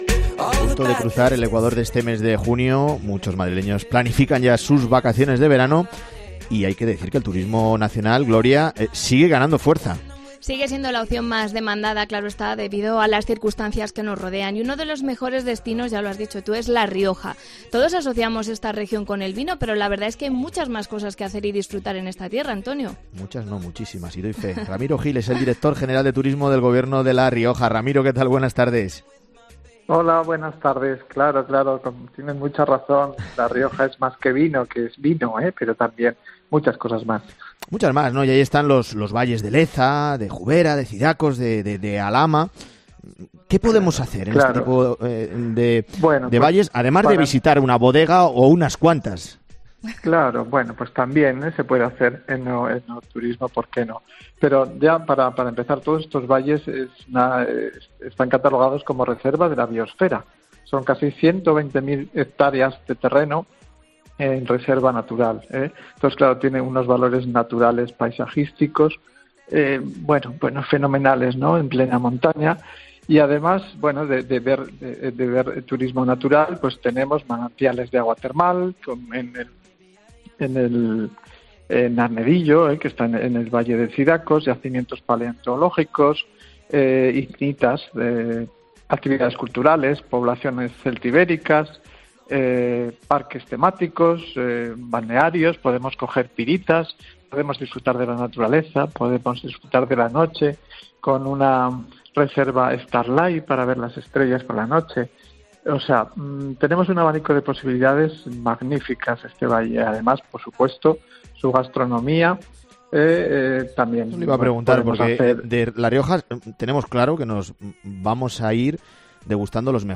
Entrevista al director general de Turismo de La Rioja, Ramiro Gil, sobre las opciones vacacionales de la zona